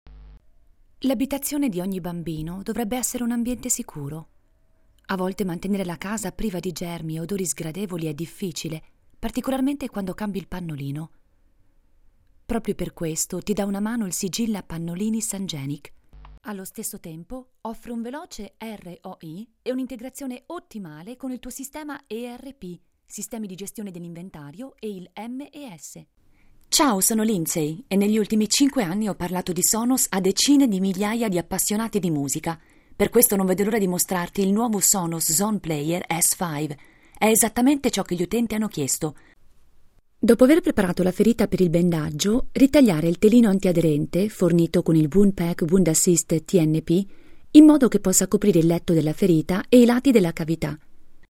Voce fresca, elegante, chiara, sofisticata, sexy, civettuola
Sprechprobe: Industrie (Muttersprache):